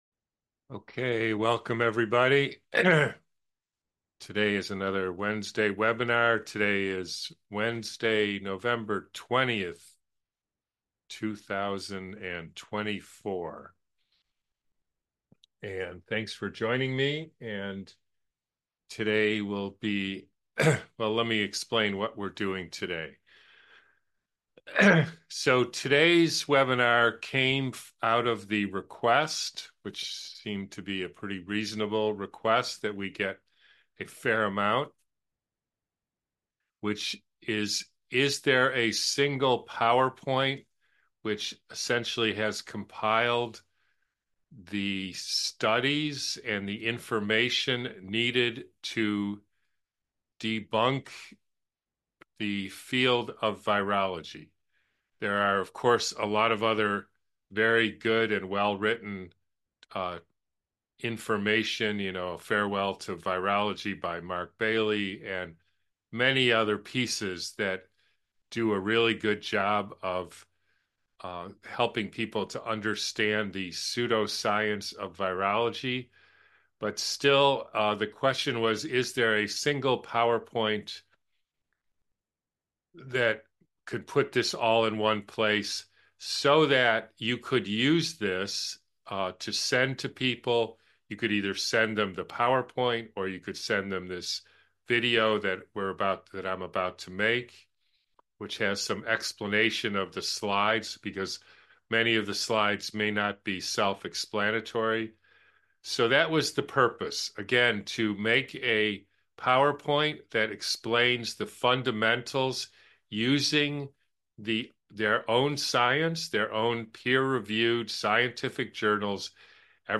The End of Virology (once again): webinar from November 20th 2024